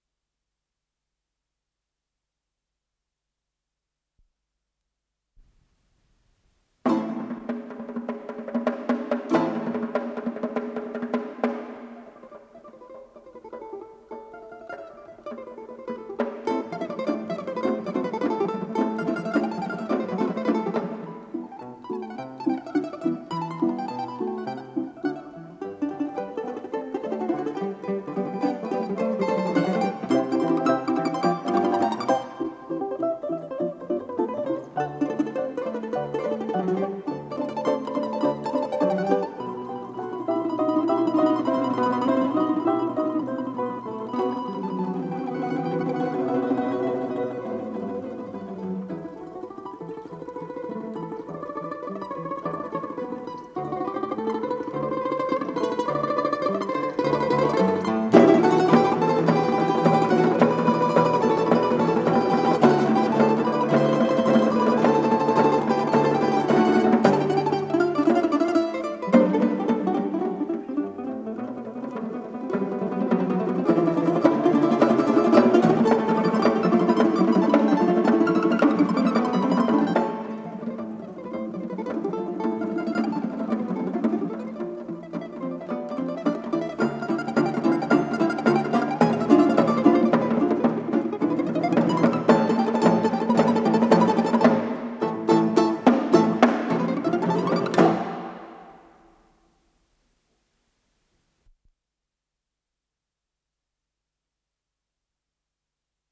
ИсполнителиКвартет русских народных инструментов "Каравай"
балалайка-прима
малая домра
домра-альт
балалайка-бас